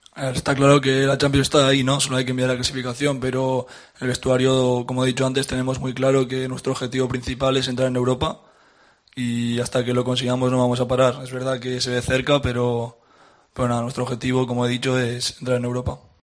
"Está claro que la Champions está ahí, pero nuestro objetivo es entrar en Europa y hasta que no lo consigamos no vamos a parar", incidió el canterano en la rueda de prensa que ofreció este viernes en Lezama antes del entrenamiento dirigido por Ernesto Valverde para preparar el partido en San Mamés frente al Girona.